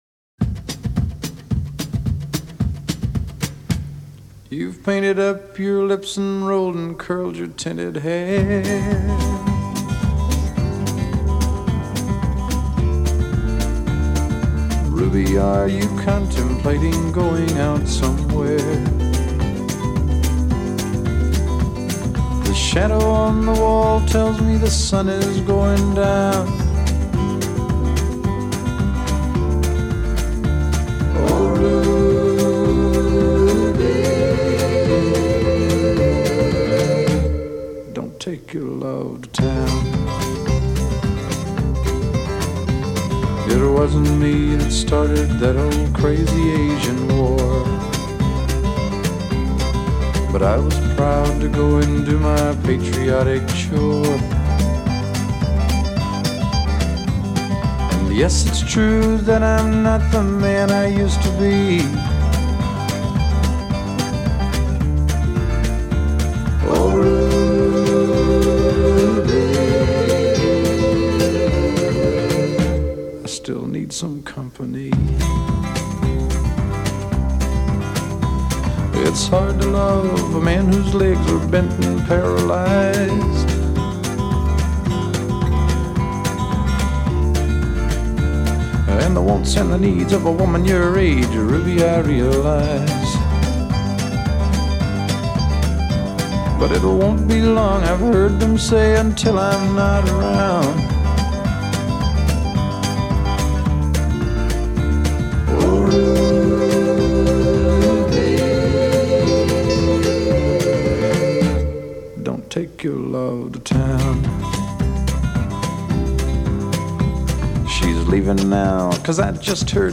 And finally he almost whispers: